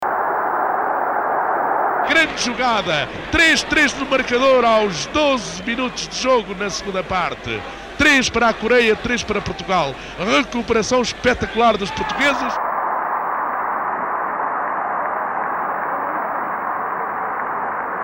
Explicação Artur Agostinho relata, ao microfone da Emissora Nacional, o jogo que opõe Portugal e Coreia do Norte, a contar para os quartos-de-final do Mundial de 1966.